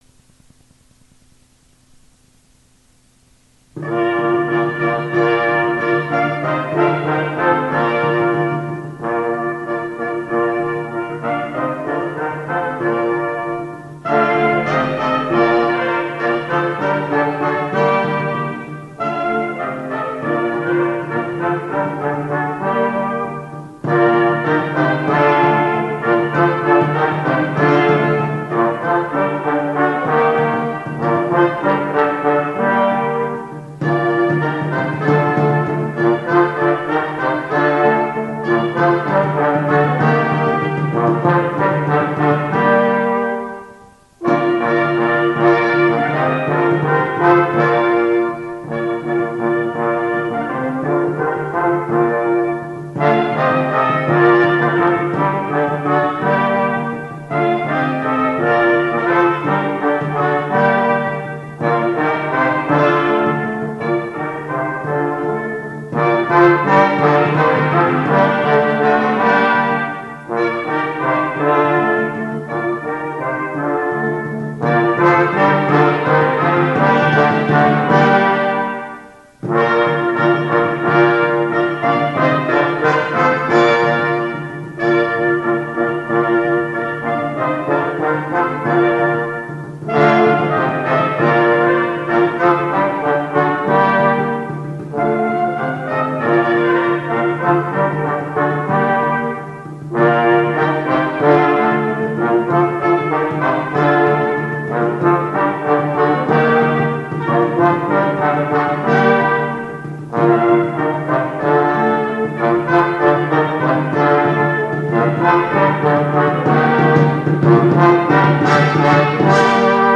Side A, digitized from cassette tape:
Two Renaissance Dances, 16th century anonymous (brass ensemble).